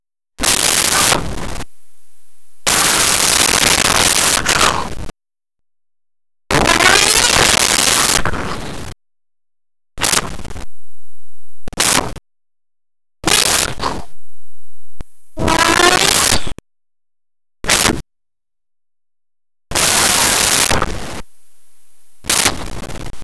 Yelling Male Karate Action
Yelling Male Karate Action.wav